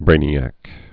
(brānē-ăk)